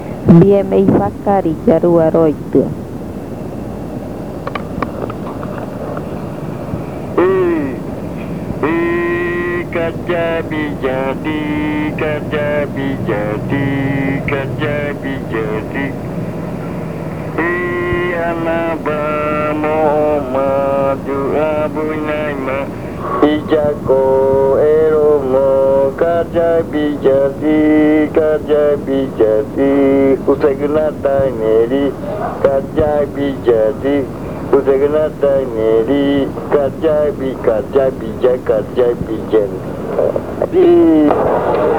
Se puede cantar en el día y también en la noche.
It can be sung during the day and also at night.
This chant is part of the collection of chants from the Yuakɨ Murui-Muina (fruit ritual) of the Murui people